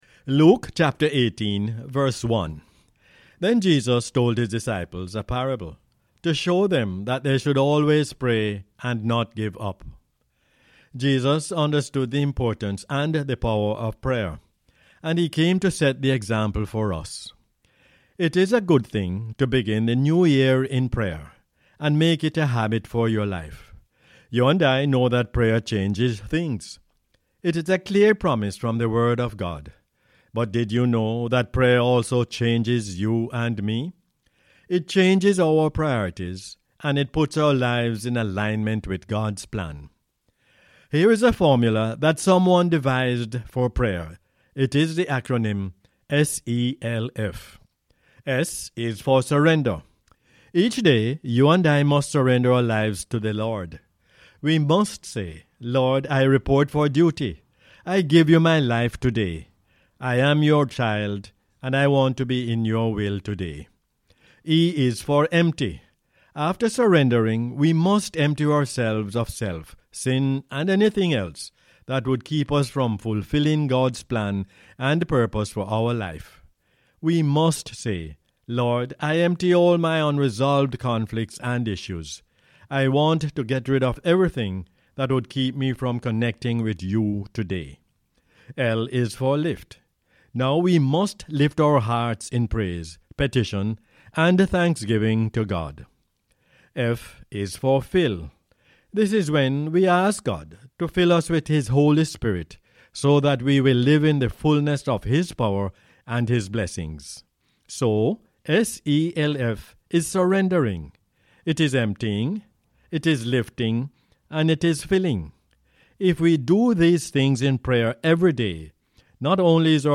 Luke 18:1 is the "Word For Jamaica" as aired on the radio on 6 January 2023.